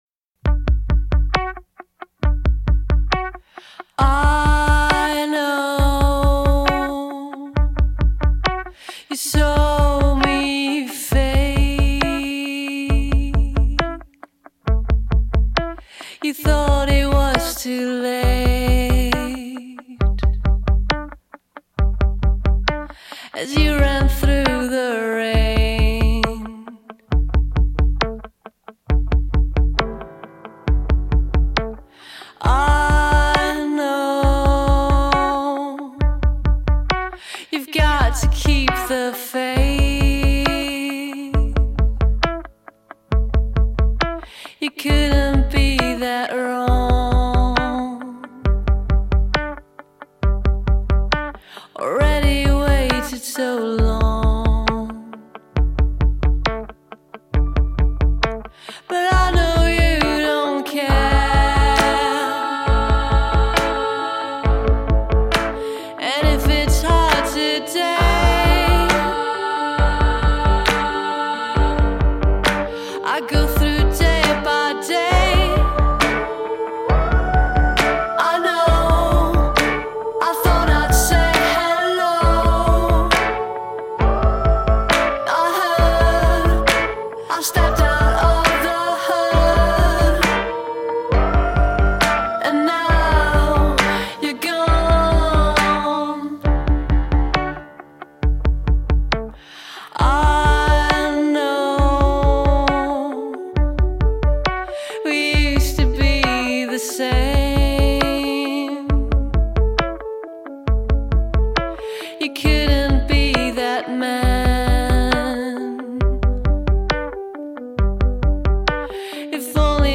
French singer/songwriter/guitarist/Rocker